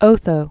(ōthō, ōtō)